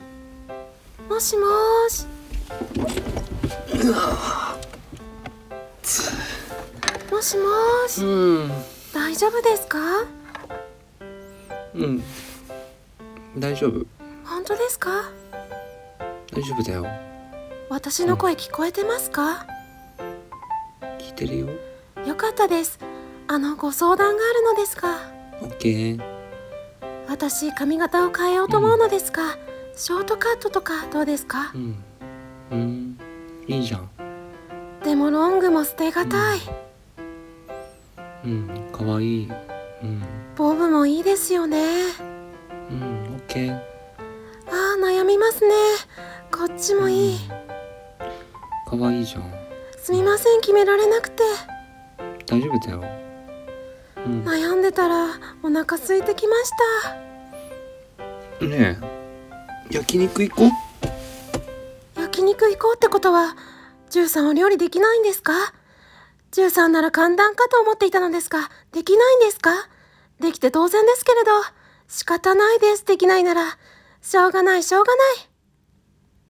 【声劇】まるで話を聞いてない男